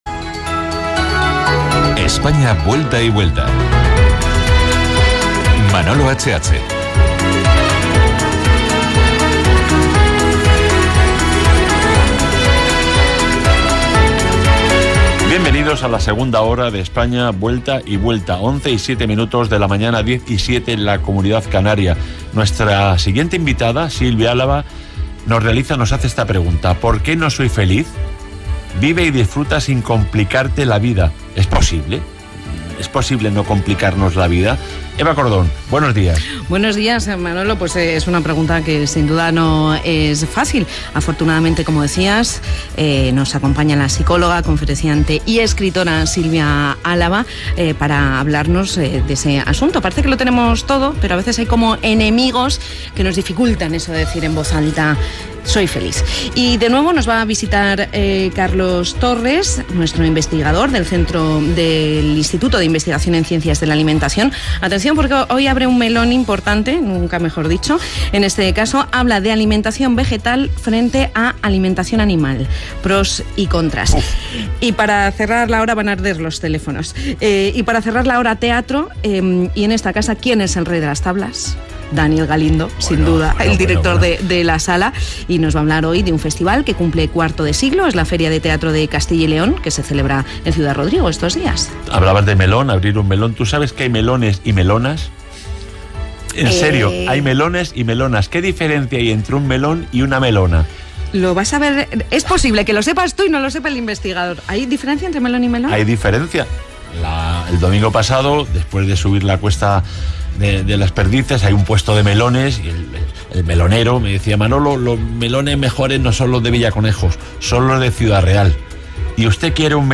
Indicatiu del programa, hora, sumari de l'hora, diàleg sobre els melons i les melones, indicatiu i entrevista
Entreteniment